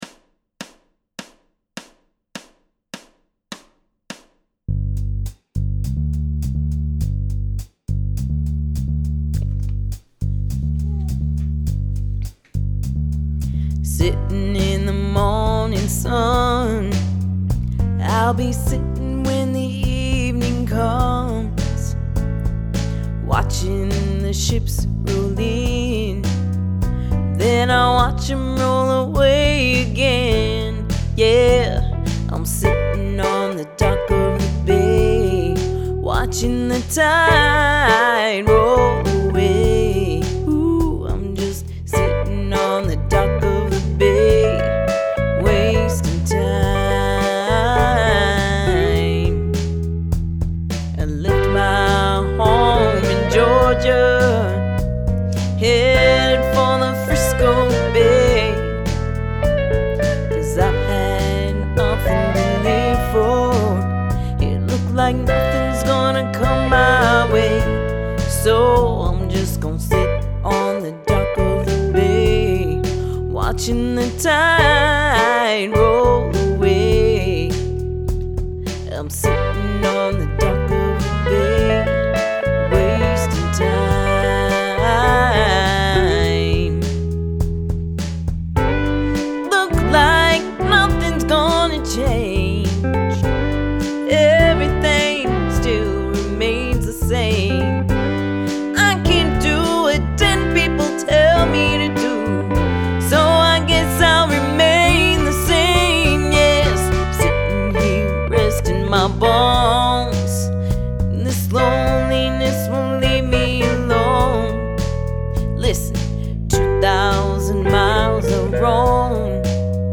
jam track available for you.